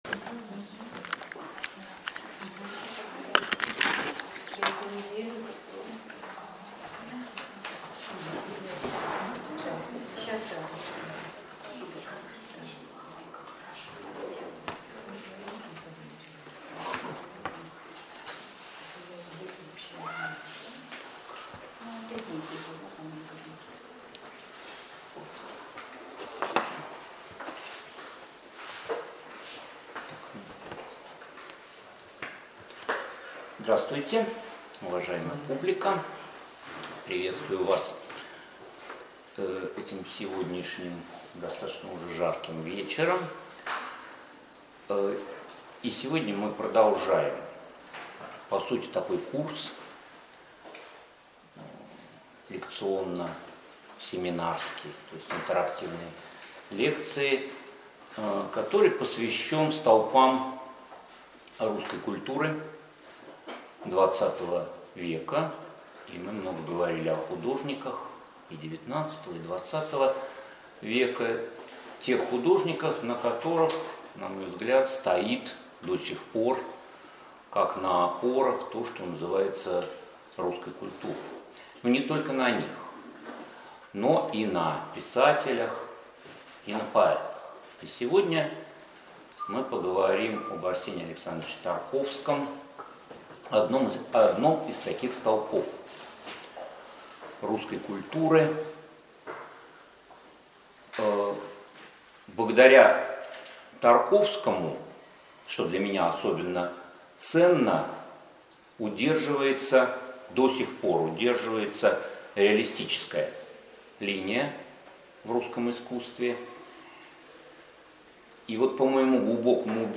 Аудиокнига Видеть детали: Арсений Тарковский | Библиотека аудиокниг